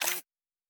pgs/Assets/Audio/Sci-Fi Sounds/Mechanical/Servo Small 6_1.wav at 7452e70b8c5ad2f7daae623e1a952eb18c9caab4
Servo Small 6_1.wav